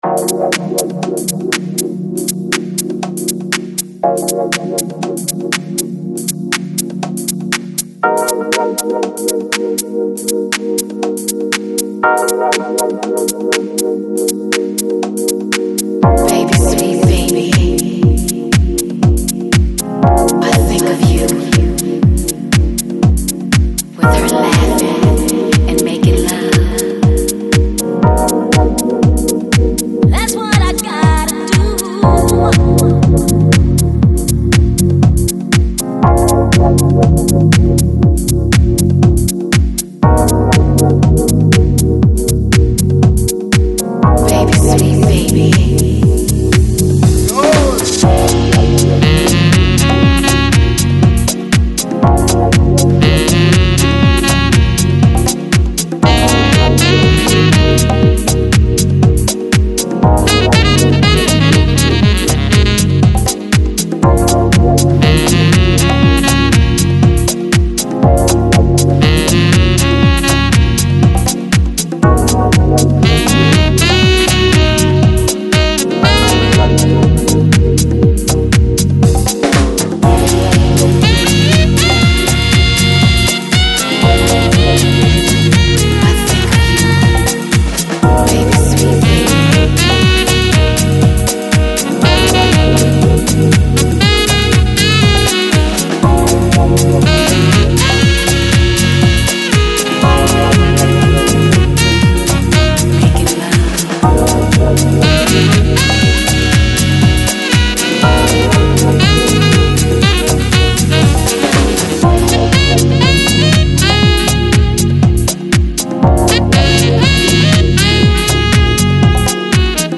Chill Out, Downtempo, Soulful House, Deep House